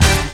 SWINGSTAB 2.wav